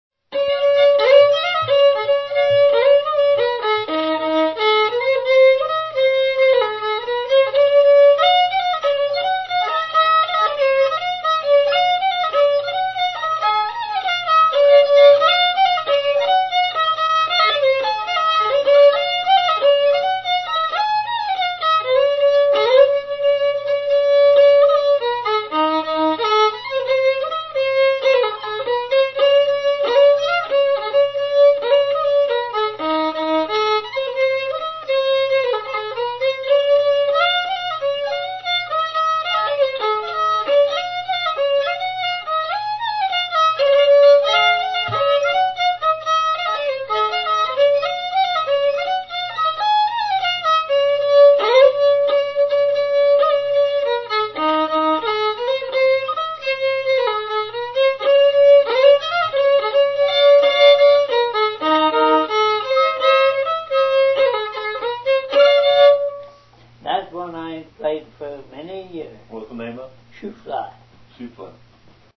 fiddle
Meter: 4/4
Breakdowns
Reels
Fiddle tunes